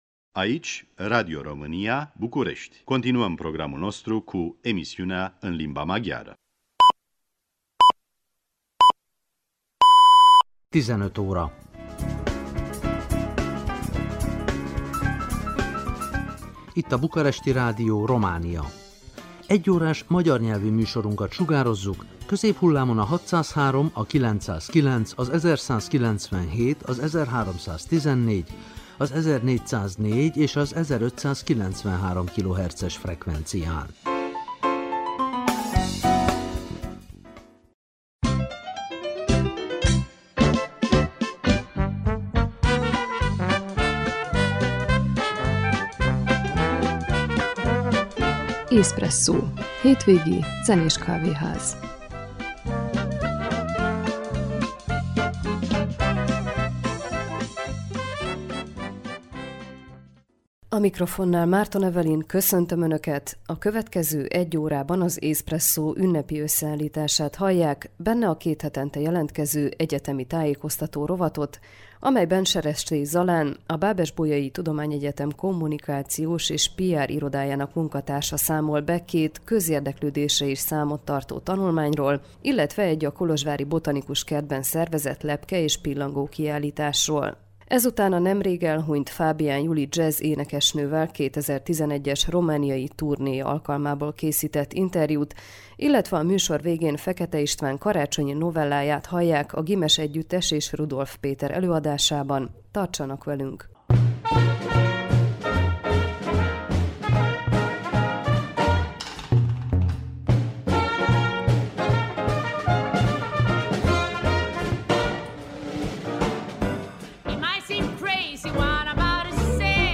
Ez után a nemrég elhunyt Fábián Juli jazz-énekesnővel 2011-es romániai turnéja alkalmából készített interjút, illetve a műsor végén Fekete István Karácsonyi novelláját a Ghymes együttes és Rudolf Péter előadásában ? tartsanak velünk!